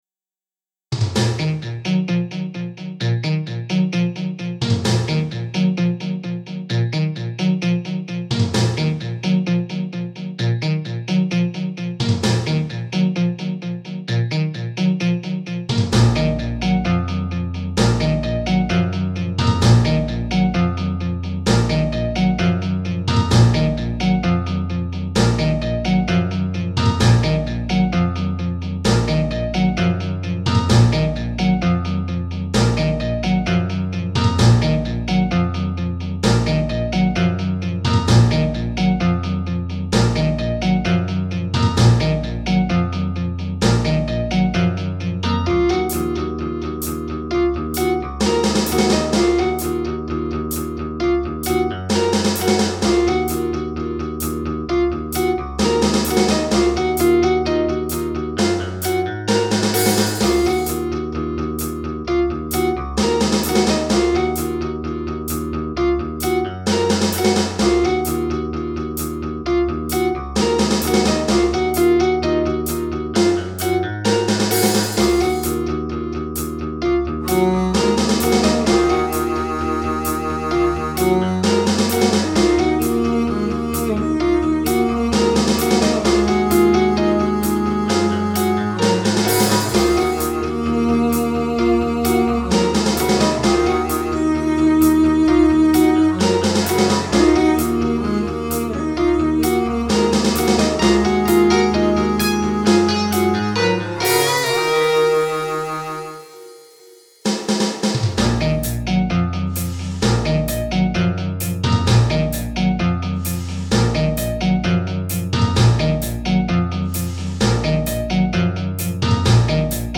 Experimental track - all feedback welcome